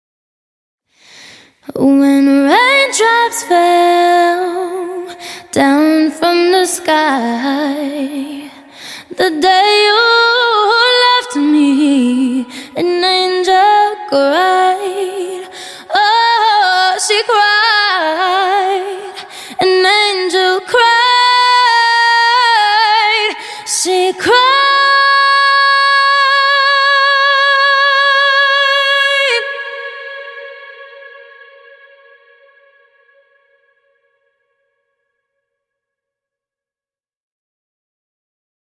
Pop, R&B